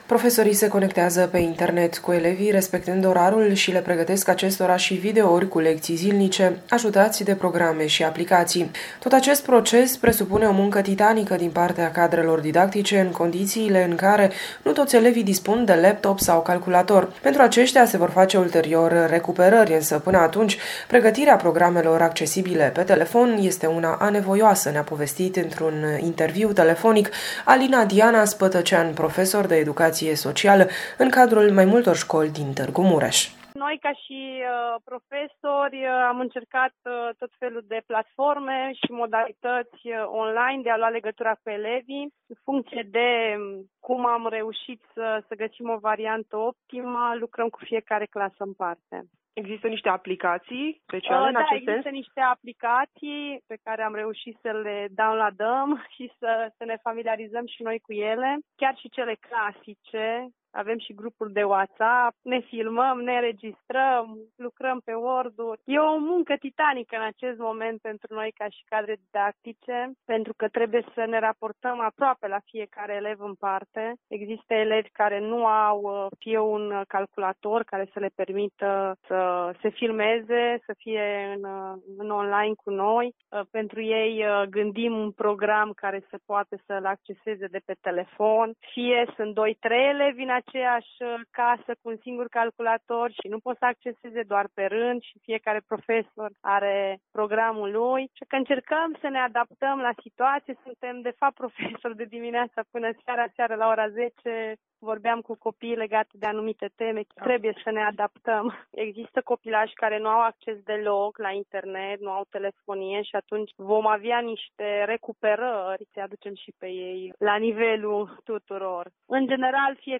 interviul
profesor de educație socială în Tg-Mureș